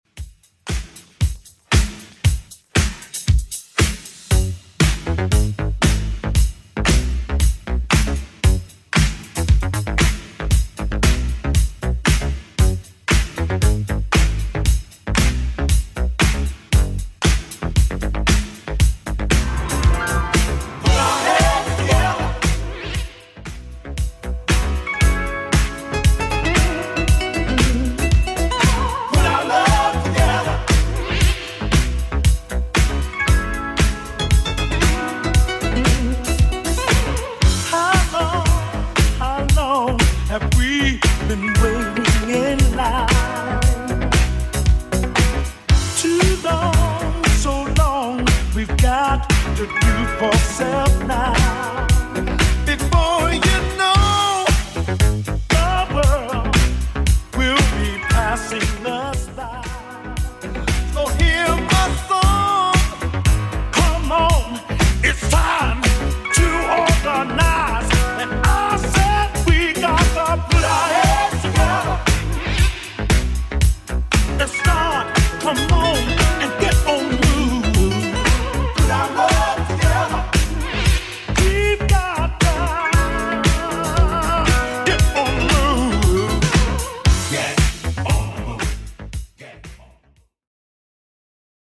Genre: 80's
BPM: 123